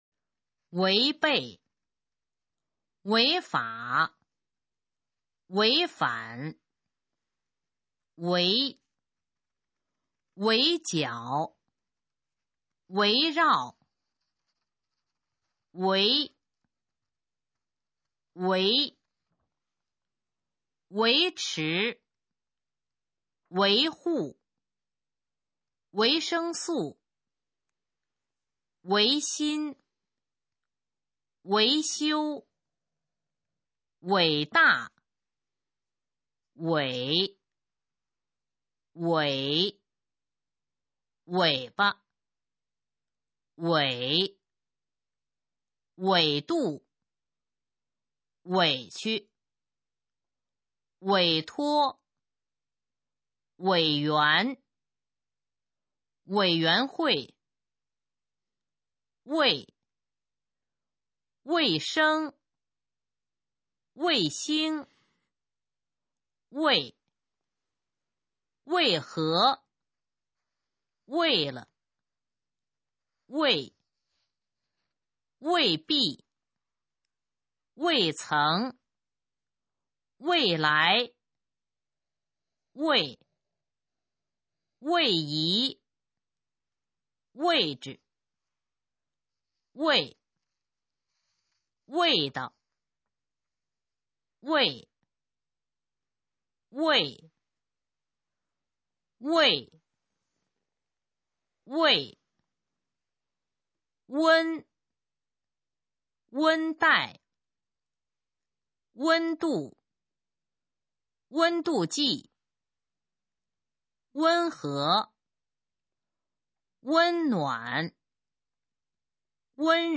首页 视听 学说普通话 作品朗读（新大纲）
《一分钟》示范朗读